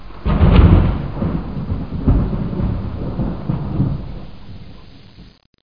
00044_Sound_Thunder1
1 channel